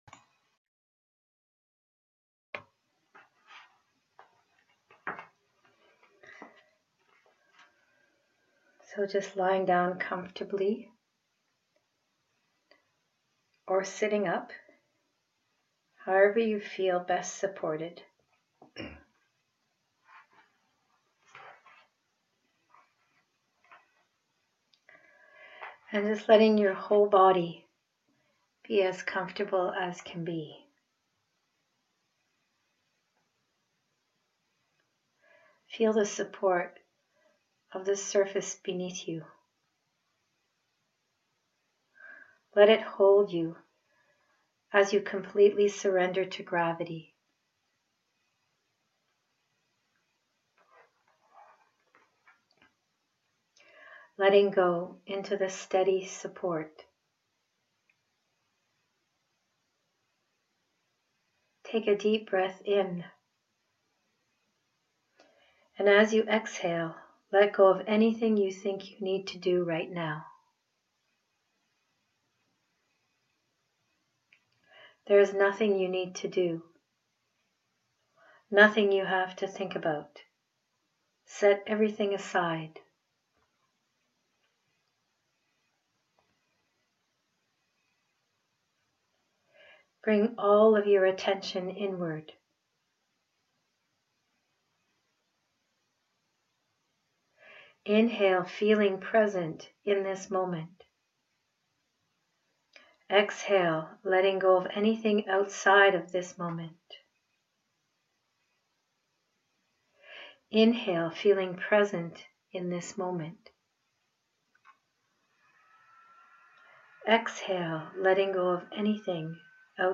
Yoga Nidras
pranic_healing_yoga_nidra_32_min_audio.m4a